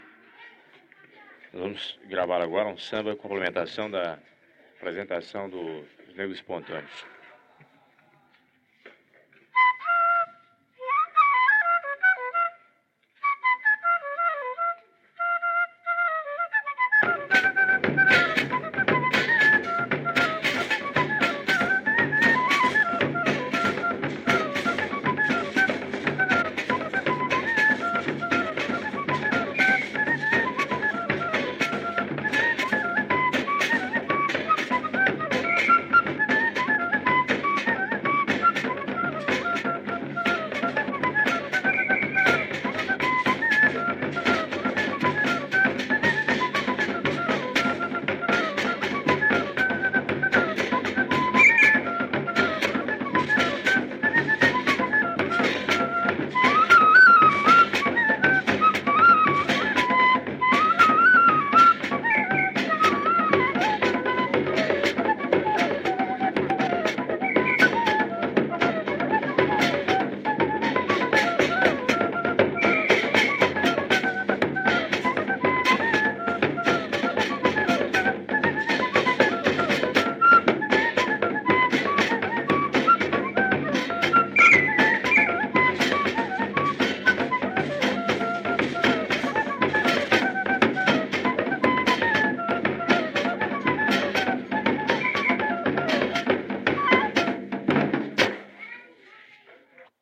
Série Música Instrumental - Festa De Nossa Senhora do Rosário
O acompanhamento é feito por uma banda cabaçal, contituída, de adufe, caixa, tambor, prato, fole e pífanos, além dos maracás das lanças. Os Pontões não cantam.
AFMI 04 Pombal 01/10/1972 Pontões da Festa de Nossa Senhora do Rosário. 6 trechos musicais com pífano, fole de oito baixos, maracás e pratos (a quinta parece um caboré), gravados pela rádio Correio da Paraíba.